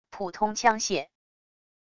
普通枪械wav音频